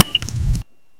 パチパチ